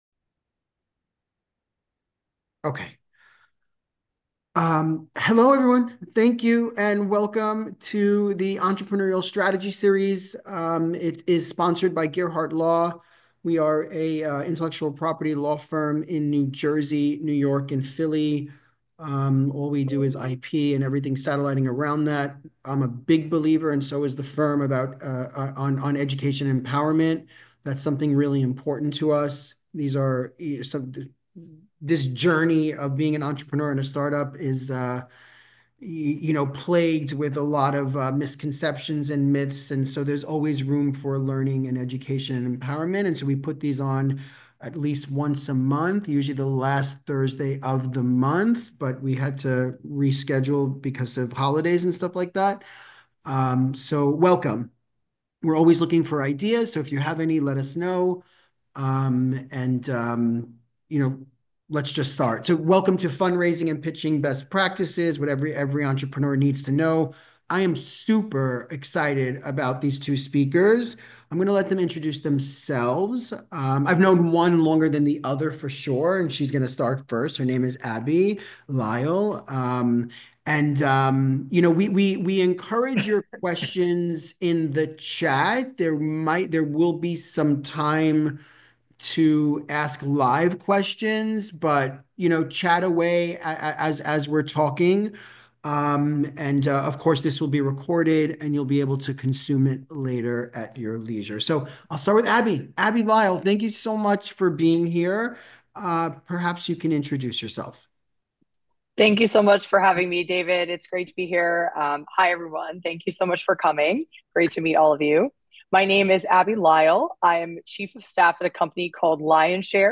In this episode of the Entrepreneur Strategy Series, two investors describe best pratices for entrepreneurs who approach investors for funding.